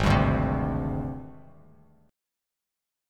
G#M7b5 chord